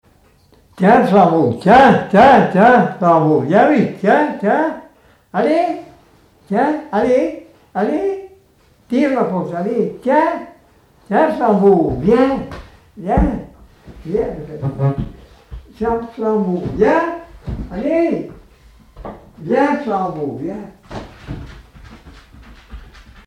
Appel au chien
Appels d'animaux, locution vernaculaires, chansons et témoignages